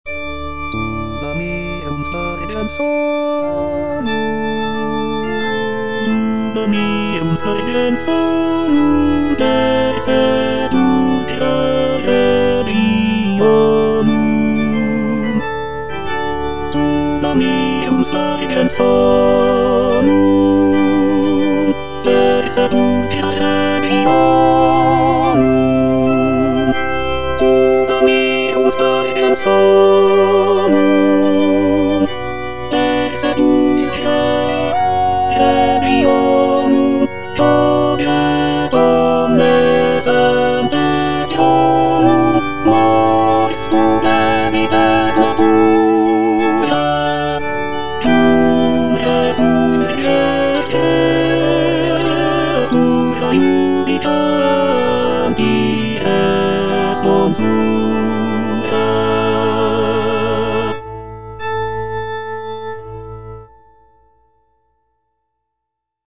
Tenor (Emphasised voice and other voices) Ads stop
is a sacred choral work rooted in his Christian faith.